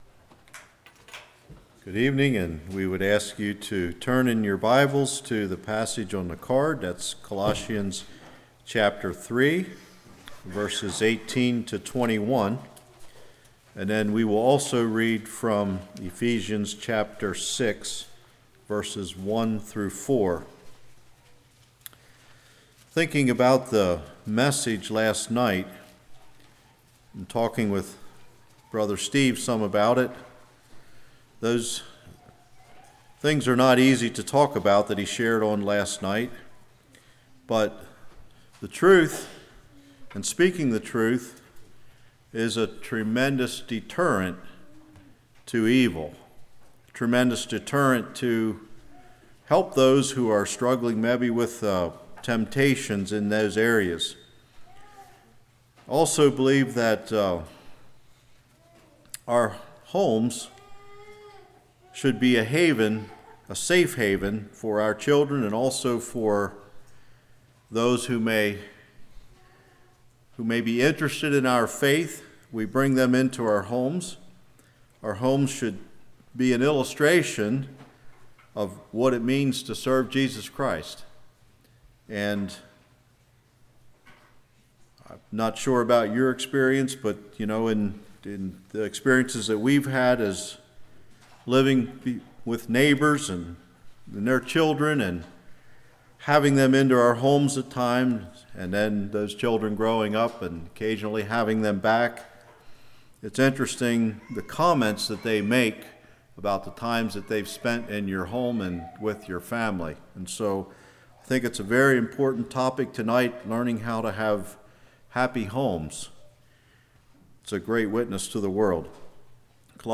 Ephesians 6:1-4 Service Type: Revival How Do I Raise My Children To Faithfully Follow Christ?